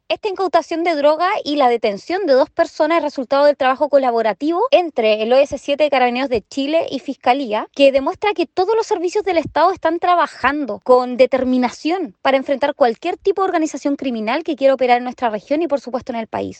Por su parte, la delegada presidencial regional de Los Lagos, Giovanna Moreira, destacó el actuar de las instituciones.